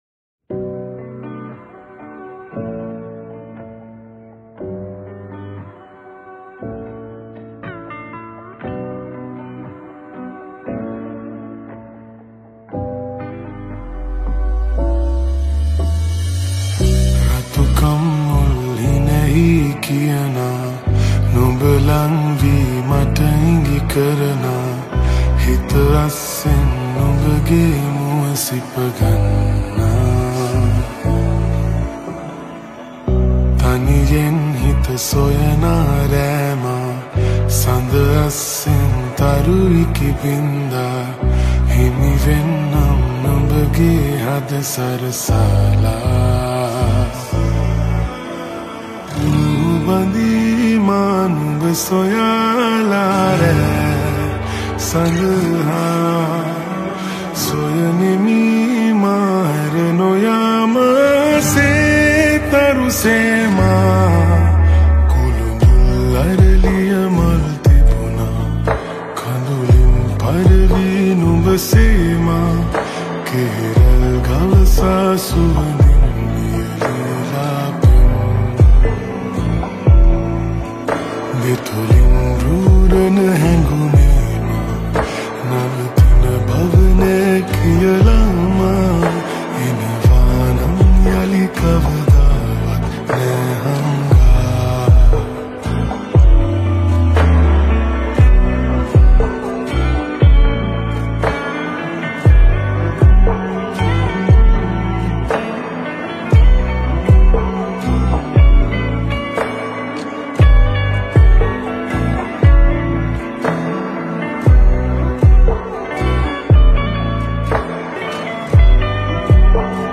(slowed+reverb)
(slowed version)